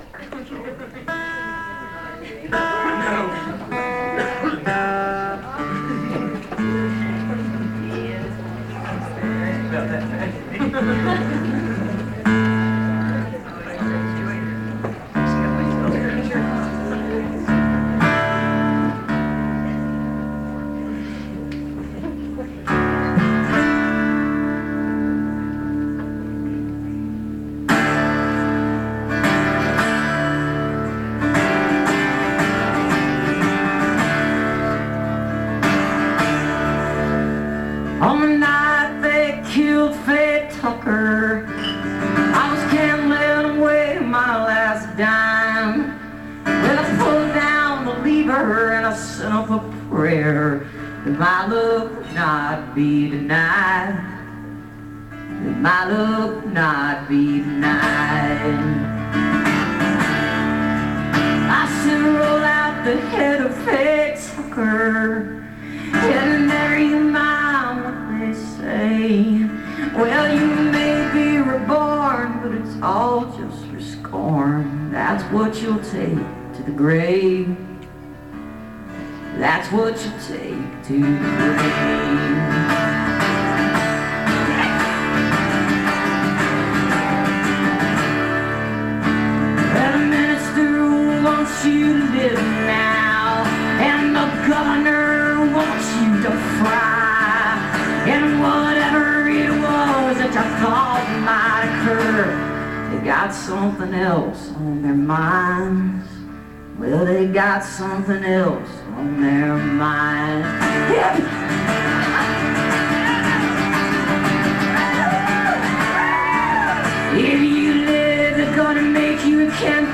(songwriters in the round)